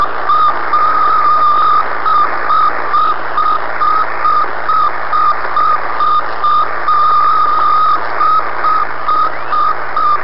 They said it was transmitting a radio signal on 20.005 MHz, close to the popular 15-meter Amateur Radio band.
Beep, Beep, Beep" signal at the time and frequency indicated.